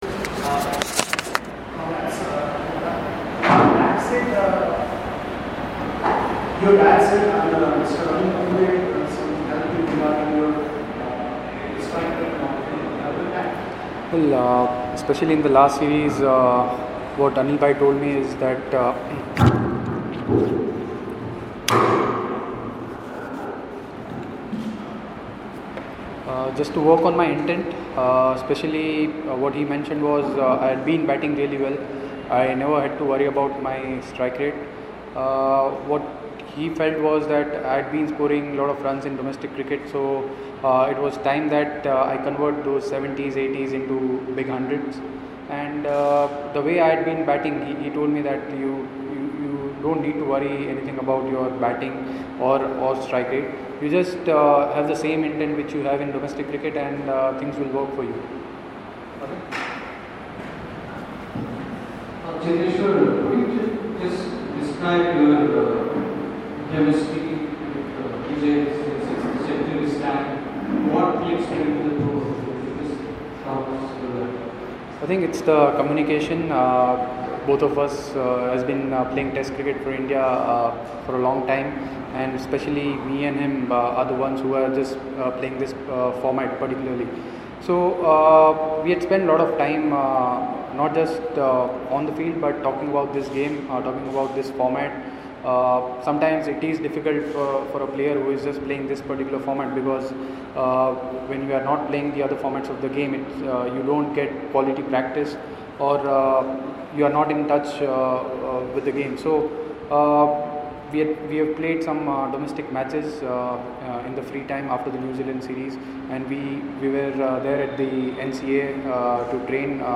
LISTEN: Cheteshwar Pujara Speaking After Rajkot Heroics